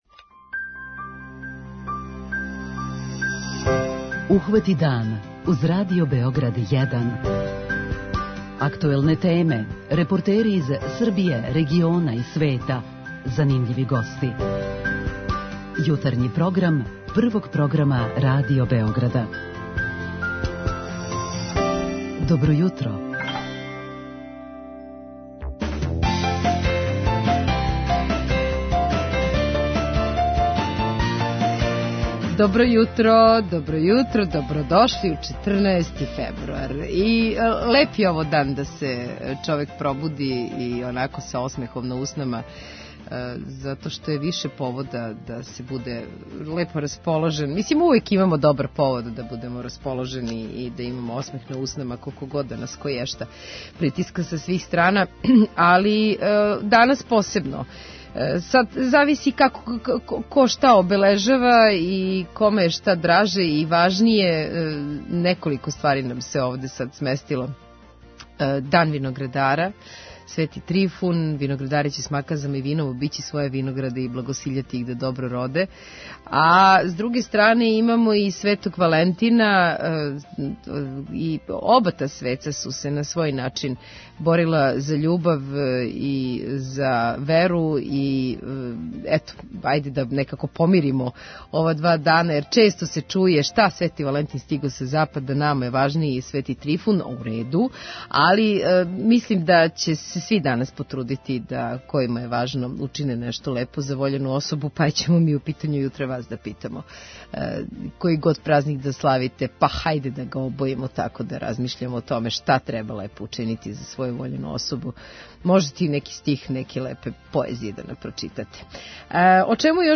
Шта је то што би могло да оплемени овај дан питамо вас у Питању јутра. преузми : 37.77 MB Ухвати дан Autor: Група аутора Јутарњи програм Радио Београда 1!